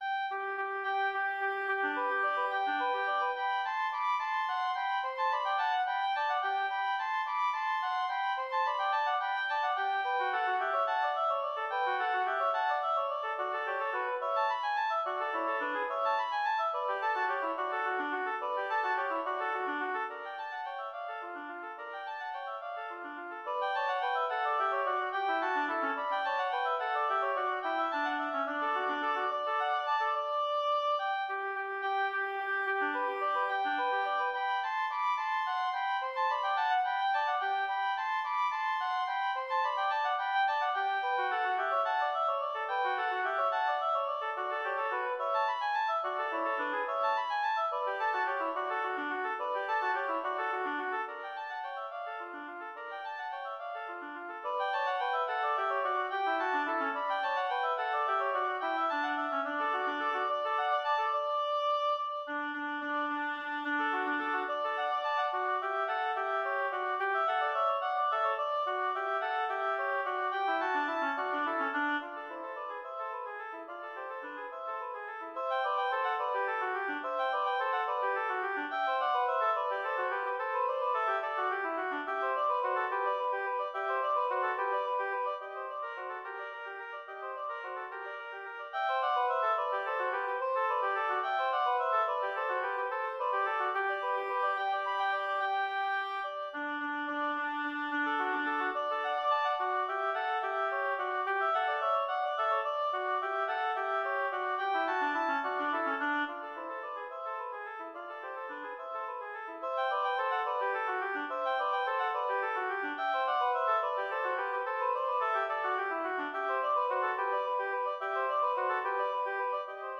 Intermediate oboe duet
double reed , oboe music